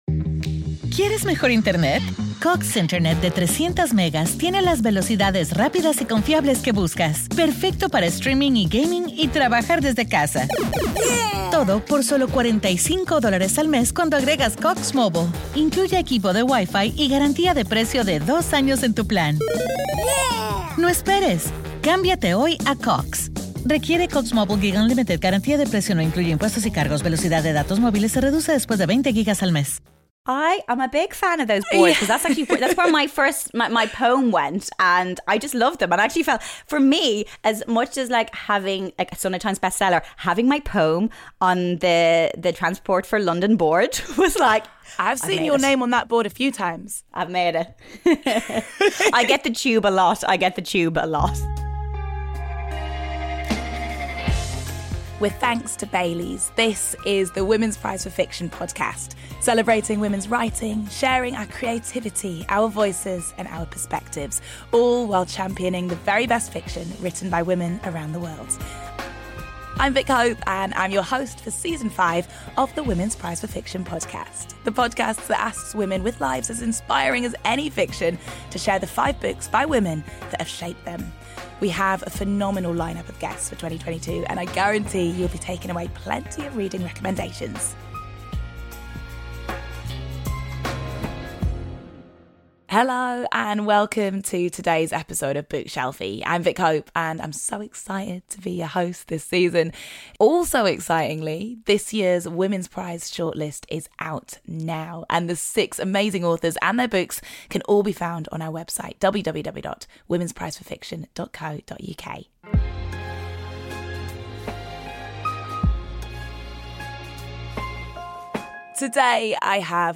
Presenter, broadcaster and author Laura Whitmore chats with Vick about their early MTV days, Love Island and why women should take up more space in a room.
Every week, Vick will be joined by another inspirational woman to discuss the work of incredible female authors.